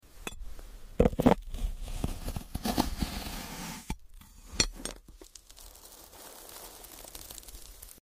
🌌✨ Today’s ASMR cut takes you beyond the stars.